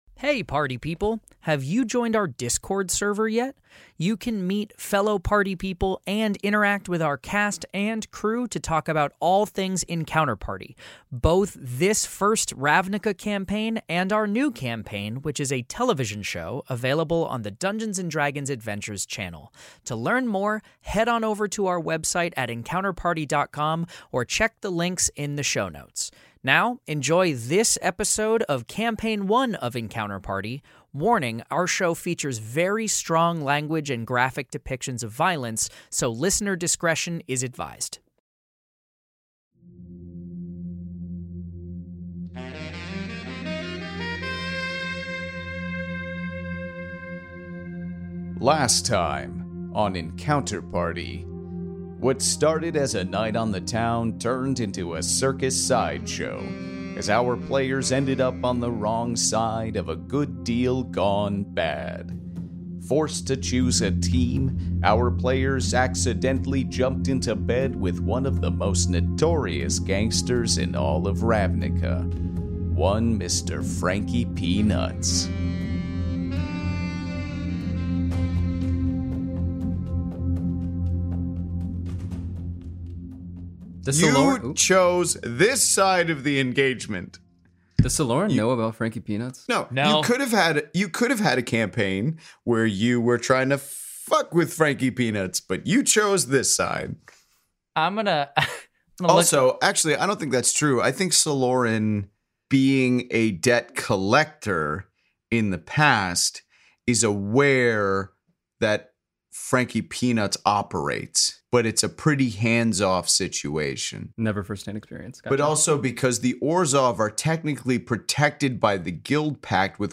Fantasy Mystery Audio Adventure
five actors and comedians